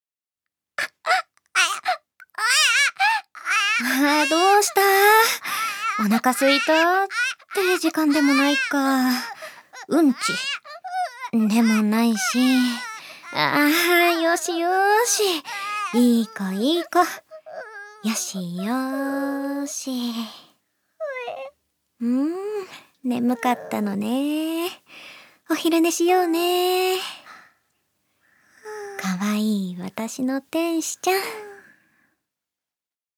セリフ１０